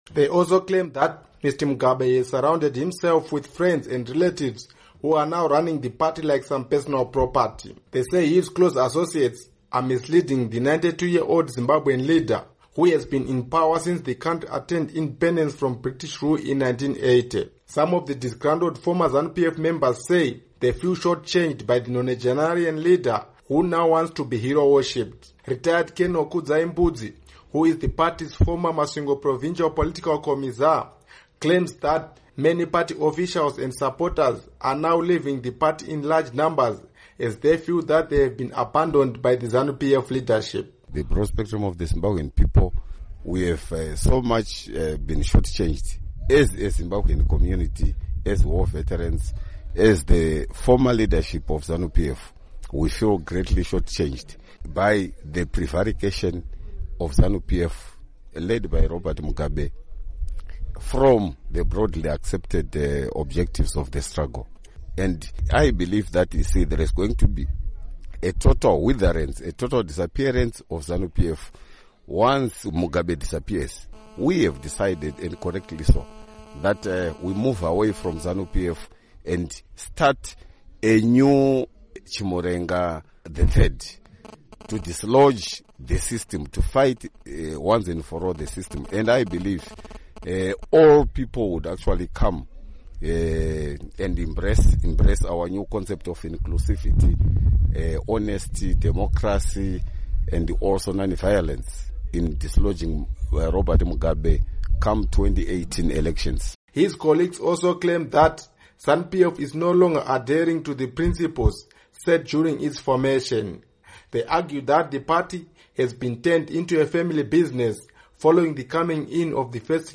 Report on Former Zanu PF Members